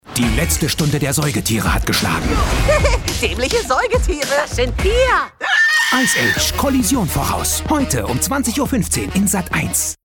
dunkel, sonor, souverän, markant
Station Voice